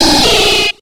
Cri de Capumain dans Pokémon X et Y.